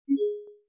Teams 提醒.mp3